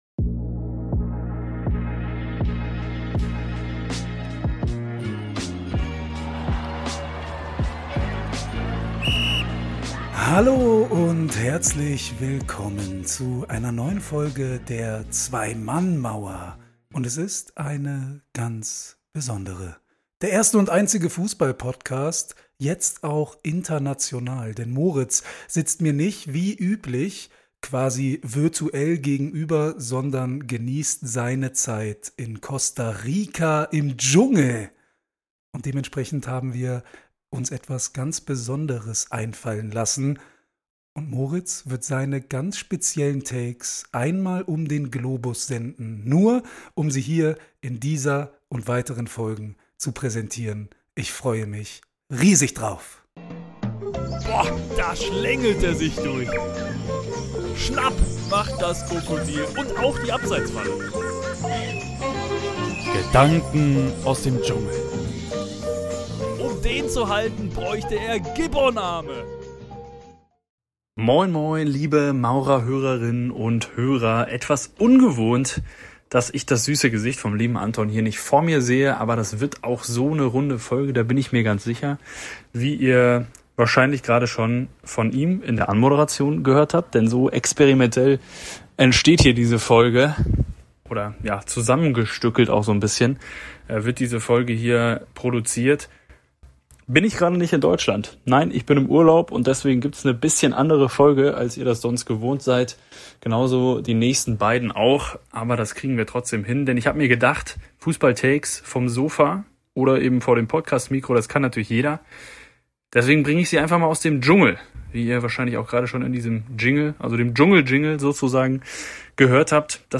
Hört selbst – falls die Papageien im Hintergrund euch nicht zu sehr ablenken.